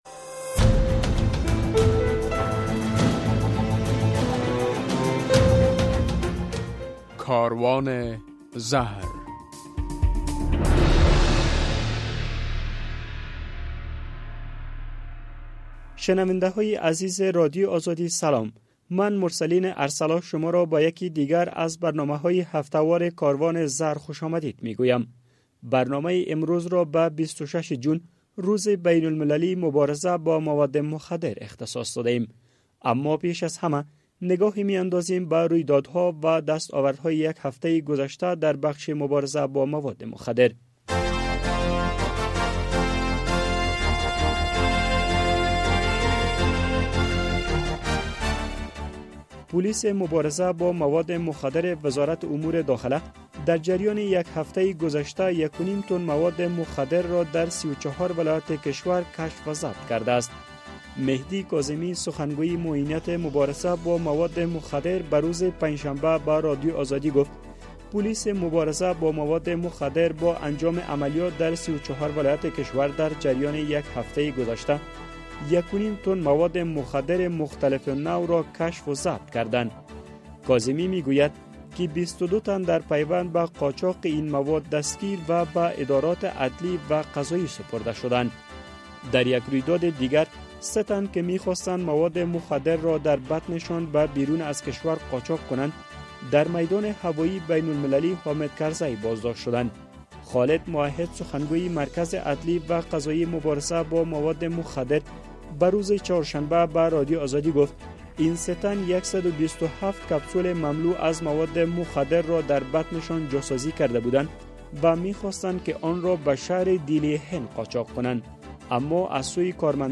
در برنامه این هفته کاروان زهر، در نخست خبرها، بعداً گزارش‌ها، بعد از آن مصاحبه و به تعقیب آن خاطره یک معتاد و ...